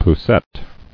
[pous·sette]